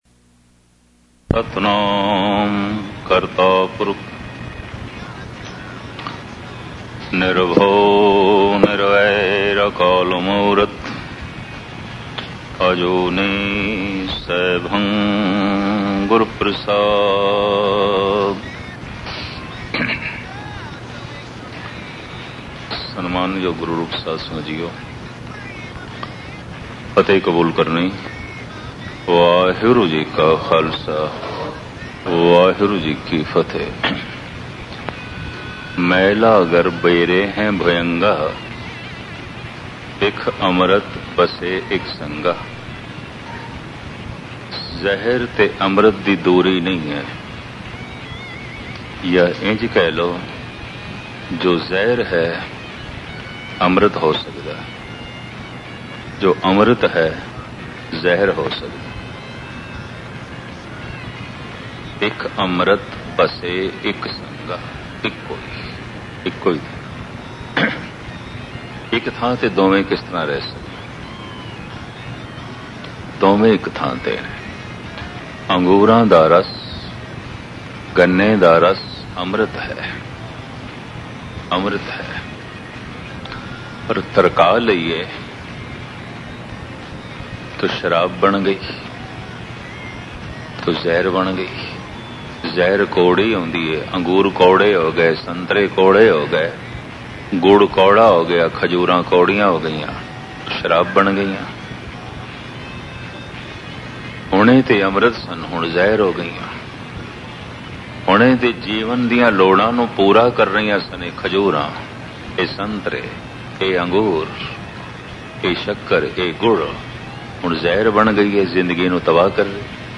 Katha